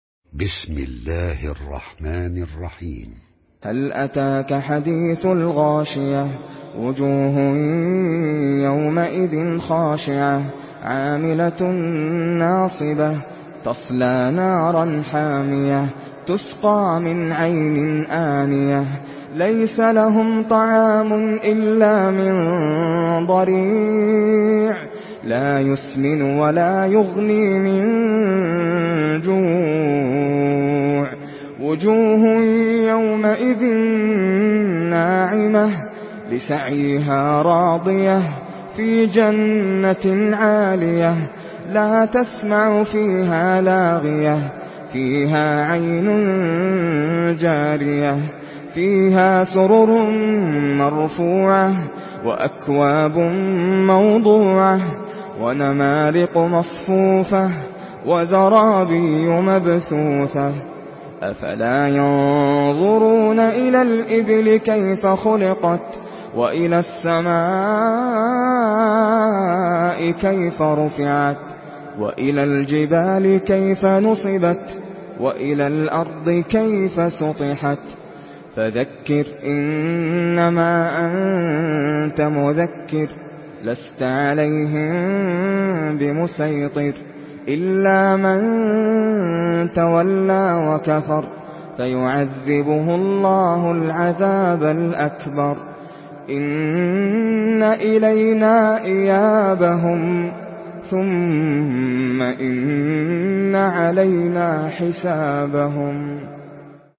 Surah Gaşiye MP3 by Nasser Al Qatami in Hafs An Asim narration.
Murattal Hafs An Asim